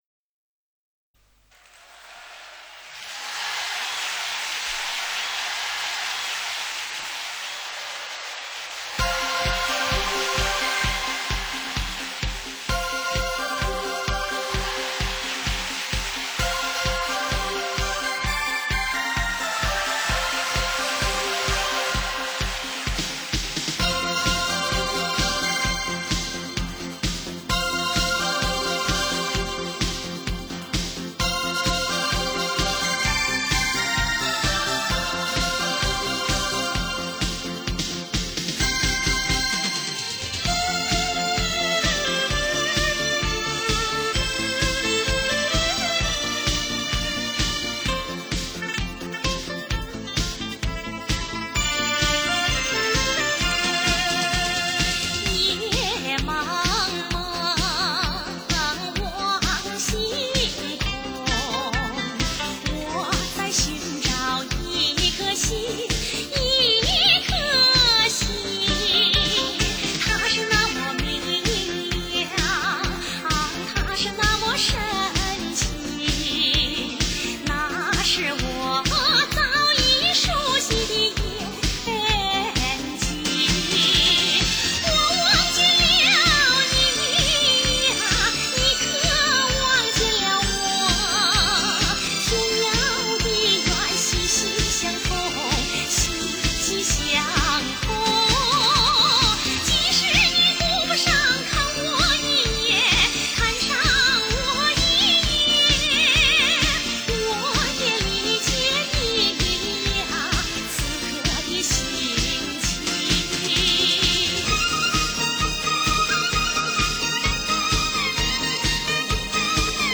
经典民歌优秀电影插曲/接连不断的演唱/连续不断的精彩
已压缩成32K试听片断